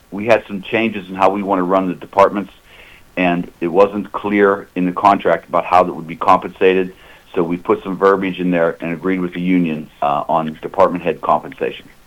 The new MOU concerns a change in compensation for Department Chairs.  Board President Rick Harper explains.